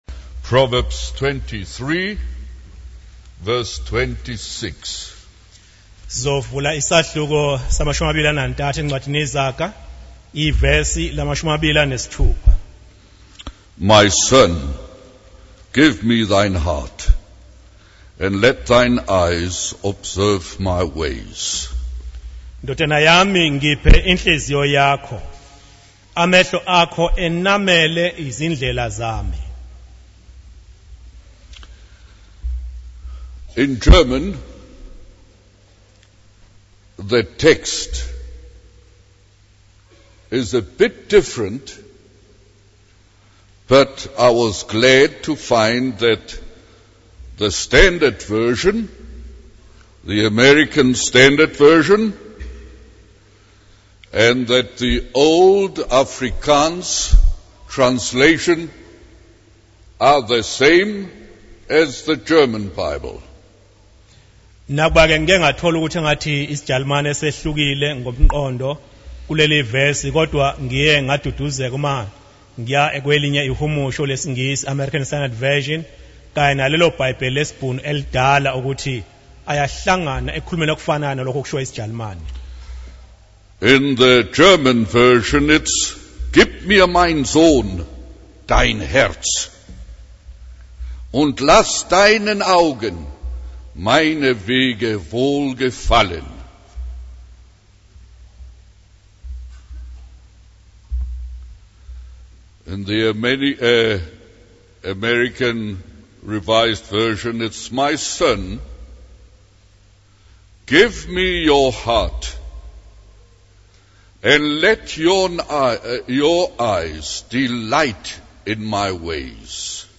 In this sermon, the preacher uses a story about a house with missing keys to illustrate the concept of giving our whole hearts to God. The buyer of the house insists on having all the keys, just as God wants our whole hearts.